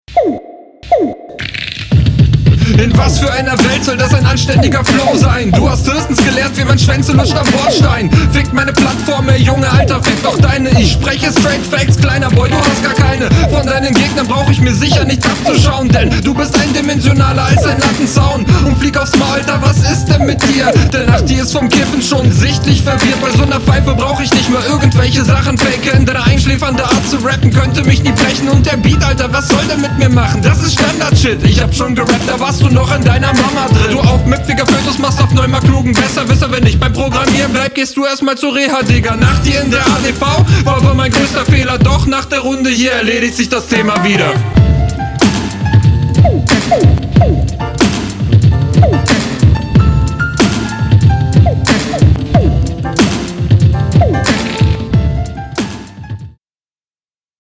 Flow: Obwohl der Beat wirklich nicht der coolste ist flogst du gekonnt und routiniert darauf.